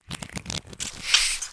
launch_pri_reload.wav